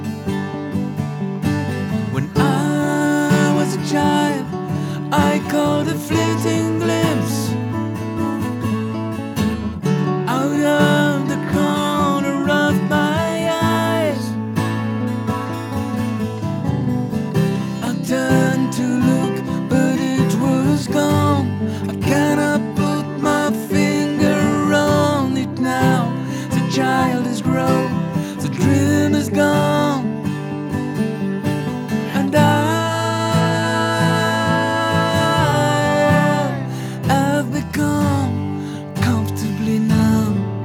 allez un petit dernier pour la route, puisque je parlais du magneto et de la bande voici un export qui passe par la bande du akai :
On dirait qu'on entend des mini wooble, mais ça reste musical. Mais je suis vraiment épaté par le son du strumming, bien crispy, j'aime bien